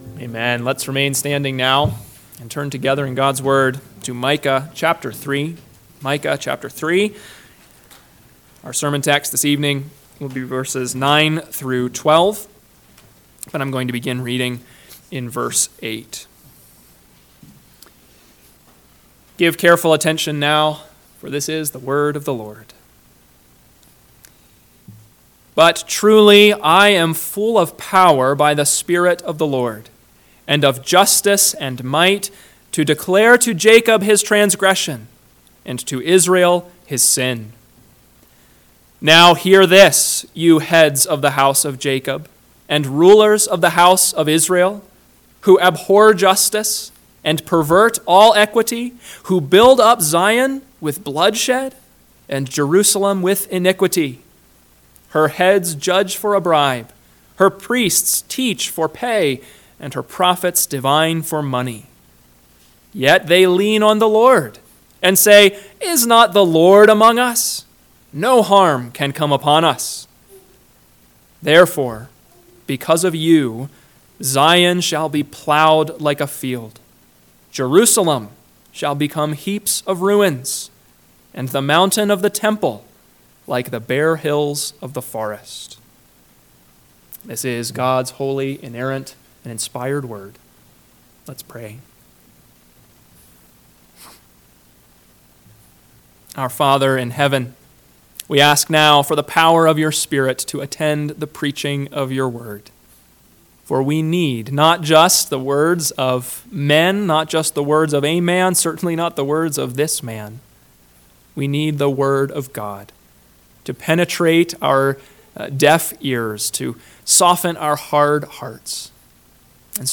PM Sermon – 10/6/2024 – Micah 3:9-12 – Northwoods Sermons